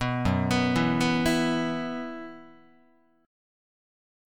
Gb7sus4 Chord